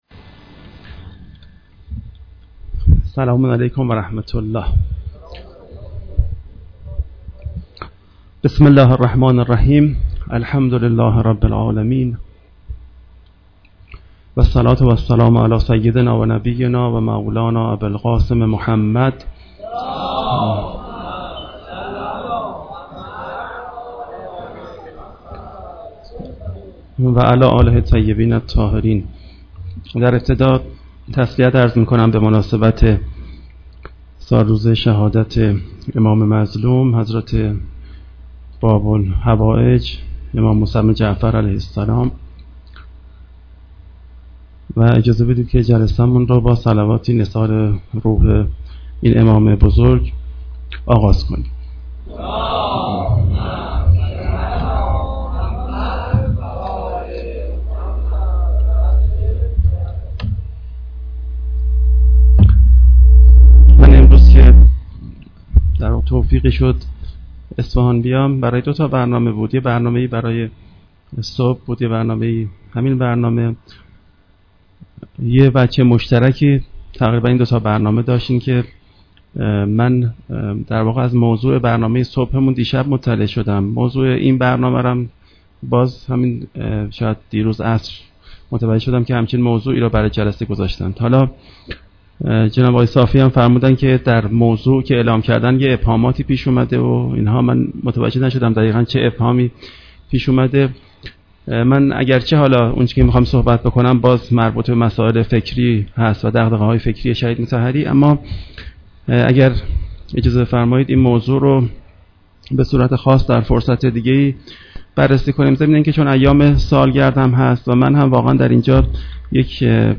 سخنرانی
در خانه بیداری اسلامی اصفهان